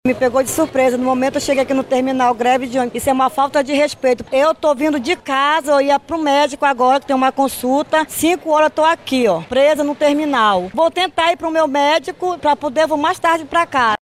Um usuária estava a caminho de uma consulta médica, próxima ao Terminal 1, no Centro de Manaus, quando foi pega de surpresa.